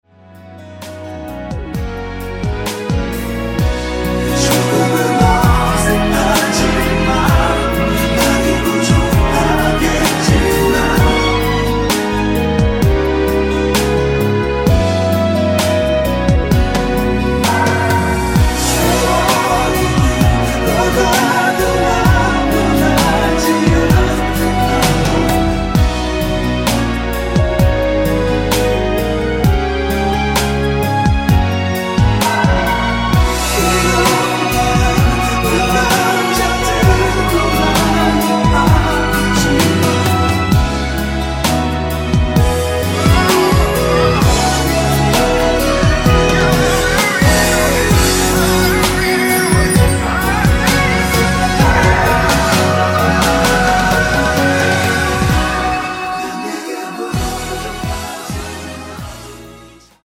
코러스 포함된 MR 입니다.(미리듣기 참조)
앞부분30초, 뒷부분30초씩 편집해서 올려 드리고 있습니다.
중간에 음이 끈어지고 다시 나오는 이유는